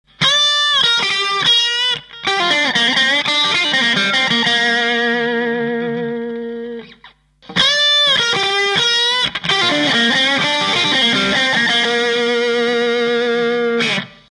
望みから程遠い音でした。